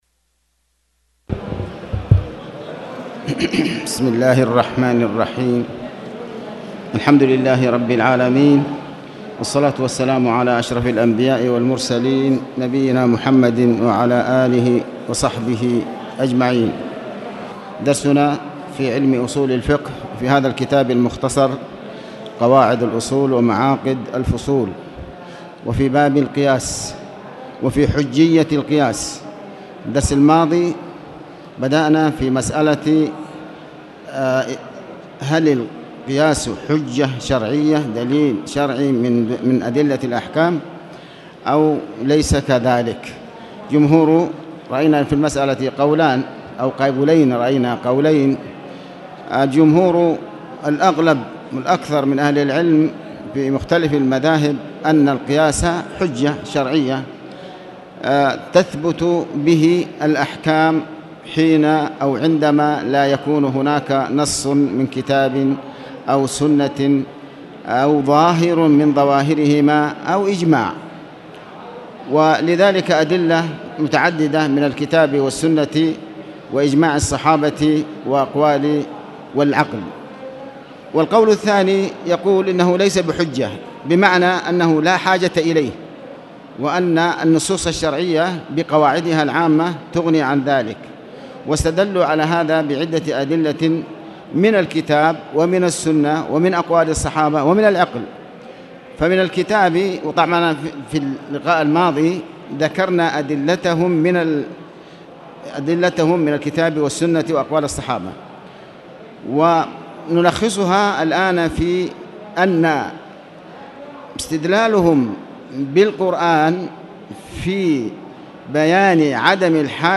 تاريخ النشر ١١ محرم ١٤٣٨ هـ المكان: المسجد الحرام الشيخ: علي بن عباس الحكمي علي بن عباس الحكمي باب القياس -حجية القياس